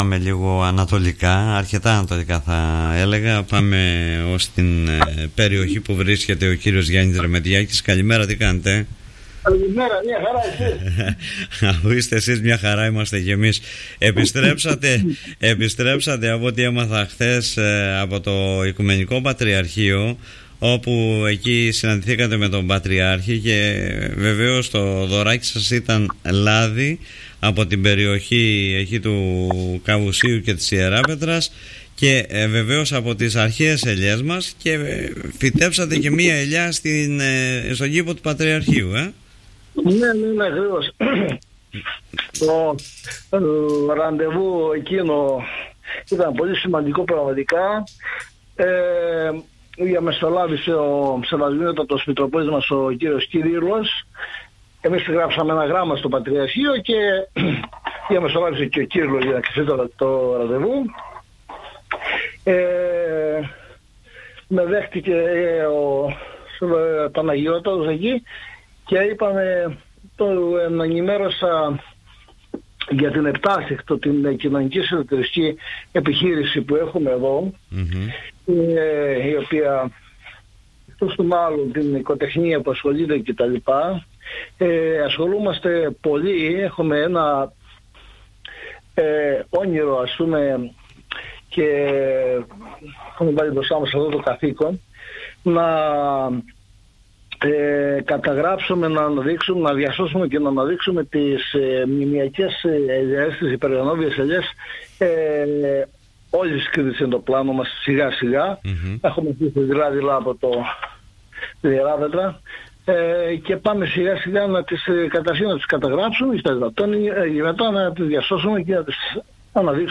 μιλώντας στον Politica 89.8